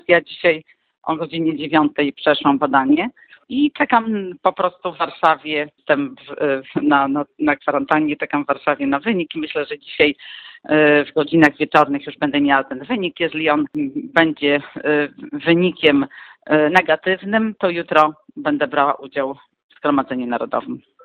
– Jestem dobrej myśli – mówi senator Małgorzata Kopiczko.